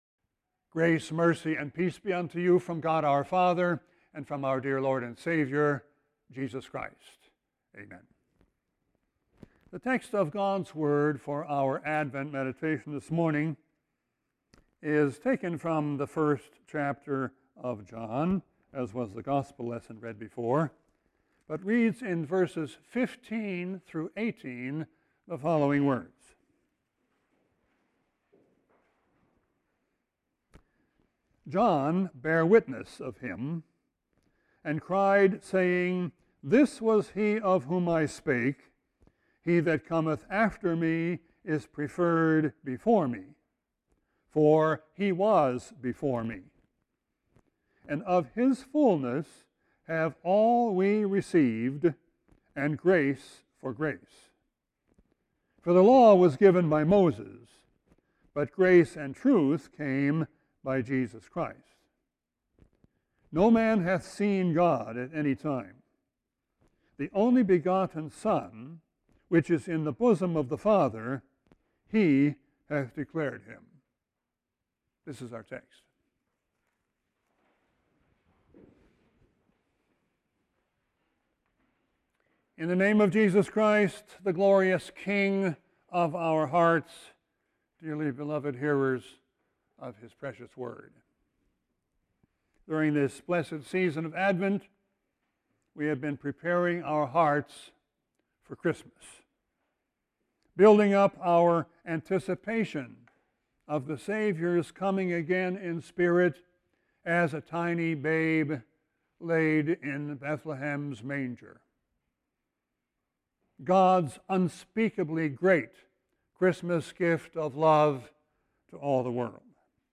I. In His glorious person; II. In His glorious gifts. Text: John 1:15-18 This text will be replaced by the JW Player Right click & select 'Save link as...' to download entire Sermon video Right click & select 'Save link as...' to download entire Sermon audio